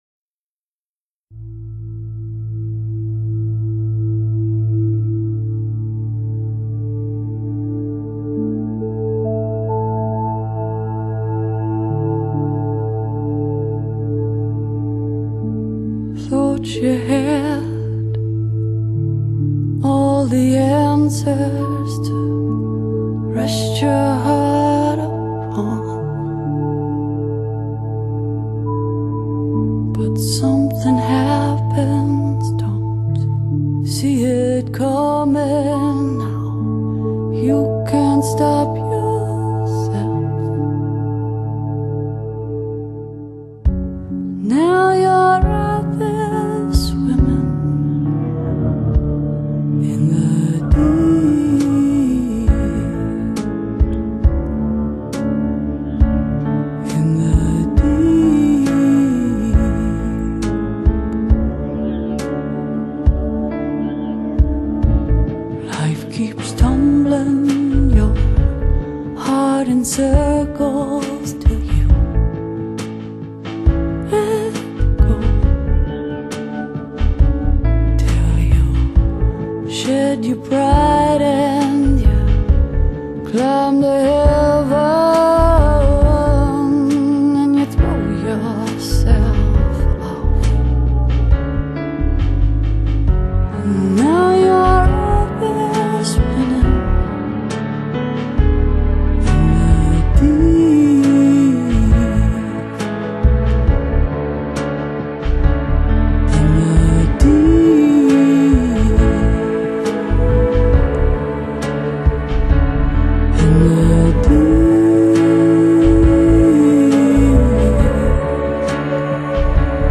这首歌曲很低沉，给人很忧伤的感觉，让人有很多的感触和想象的空间，跟电影完全想配。